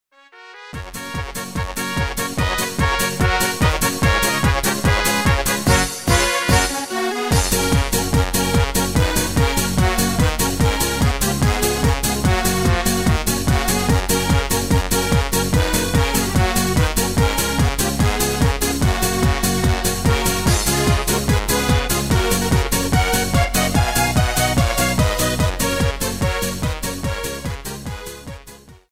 Demo/Koop midifile
Genre: Duitse Schlager
- Vocal harmony tracks